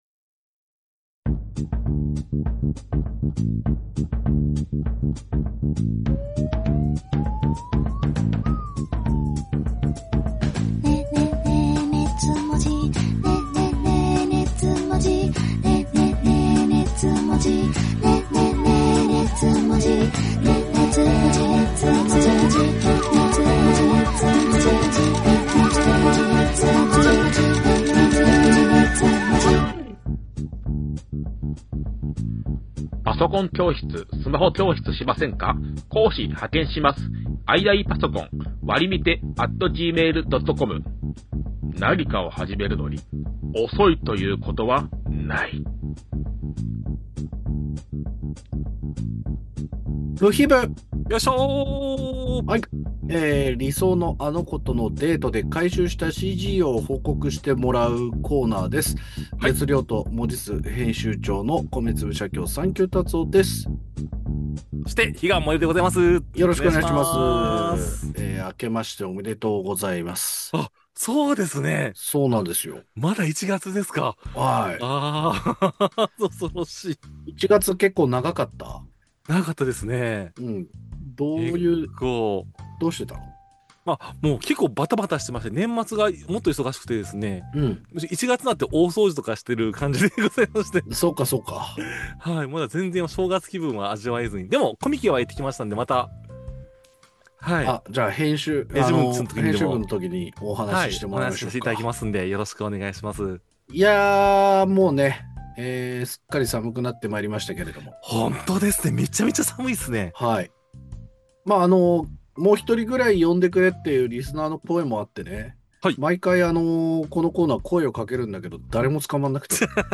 二次元を哲学するトークバラエティ音声マガジン